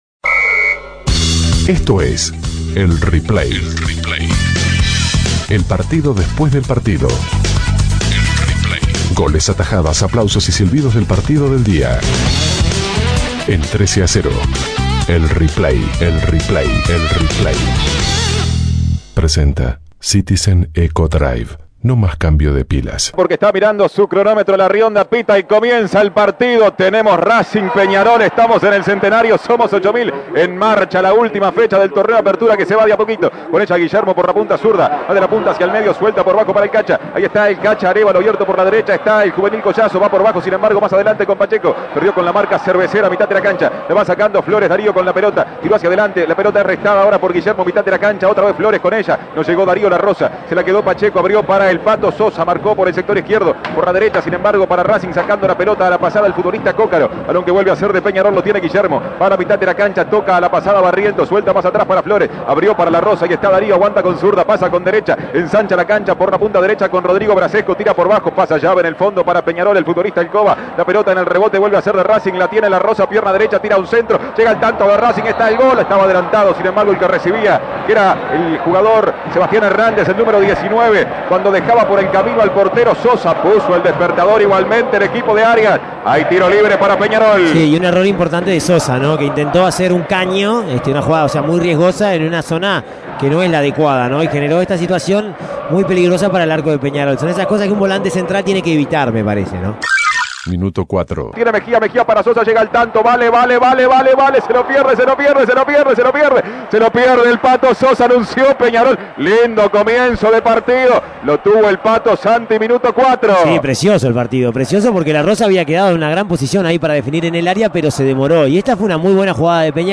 Goles y comentarios Escuche el replay de Racing - Peñarol Imprimir A- A A+ Racing derrotó por 2 a 1 a Peñarol en el Estadio Centenario y le quitó las chances de pelear por el campeonato.